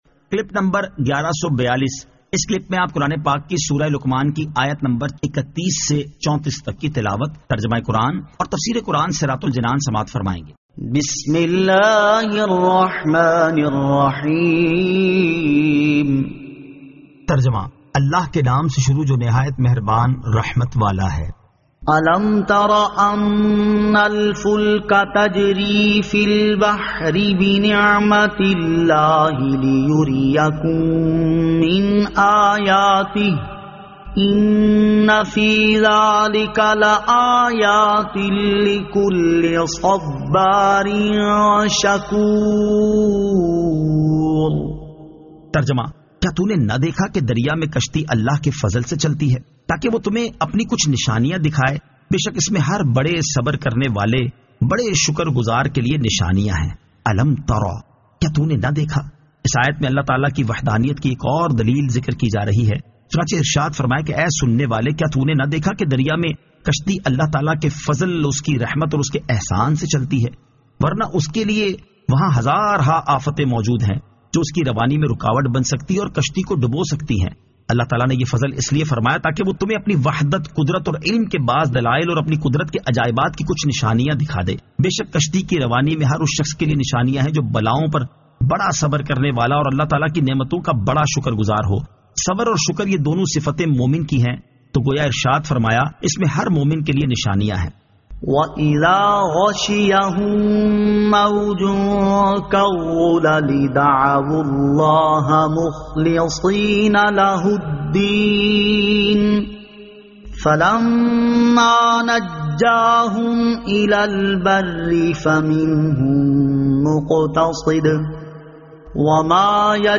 Surah Luqman 31 To 34 Tilawat , Tarjama , Tafseer